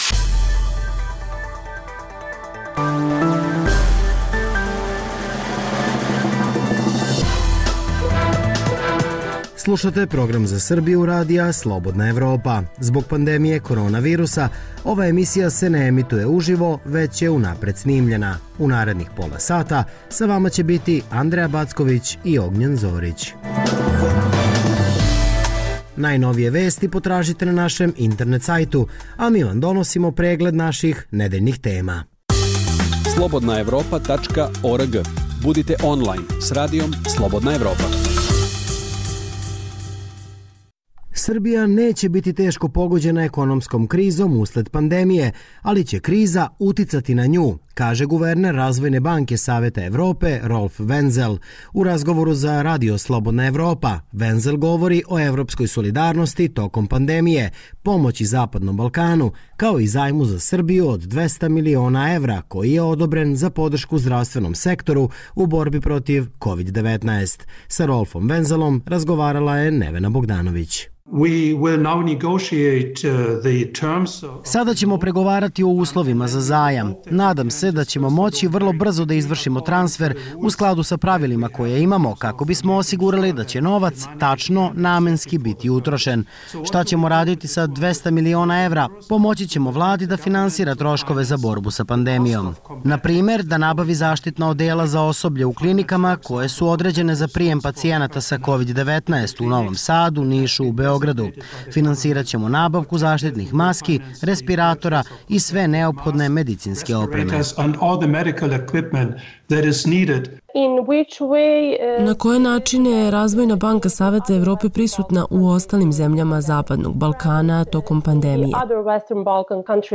Ovo je program Radija Slobodna Evropa za Srbiju. Zbog pandemije korona virusa ova emisija je unapred snimljena.